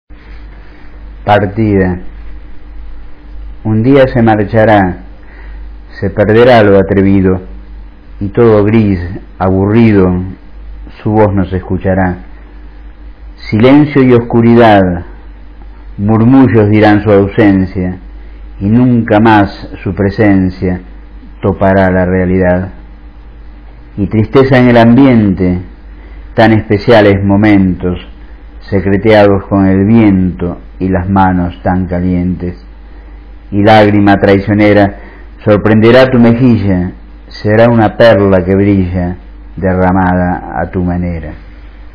Recitado por el autor (0:41" 164 KB)